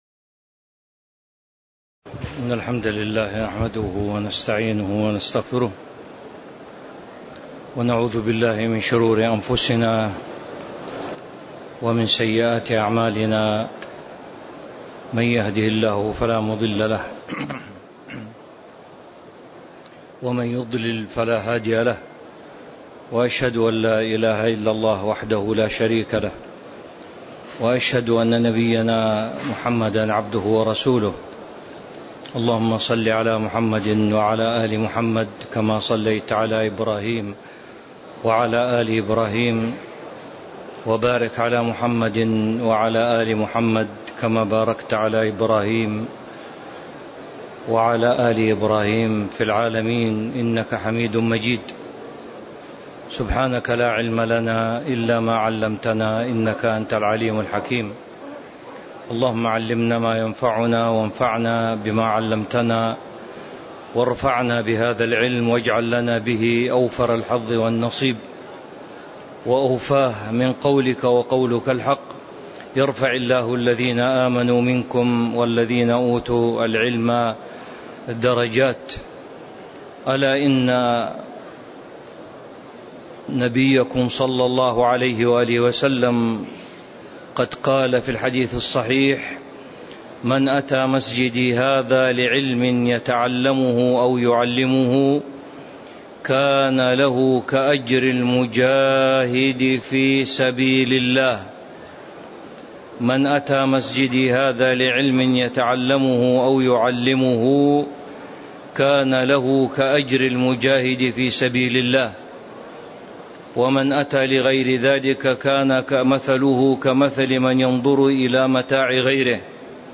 تاريخ النشر ١ ذو الحجة ١٤٤٣ هـ المكان: المسجد النبوي الشيخ